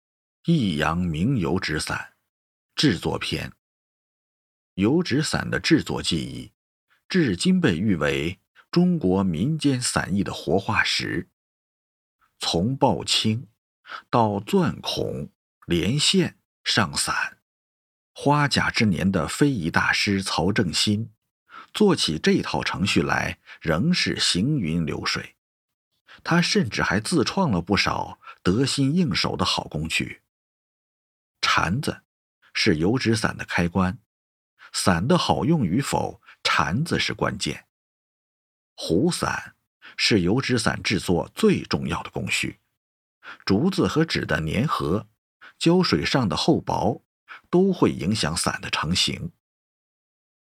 娓娓道来 文化历史
磁性男中音，擅长不同类型的纪录片，舌尖纪录片配音、人文历史配音、讲述配音等。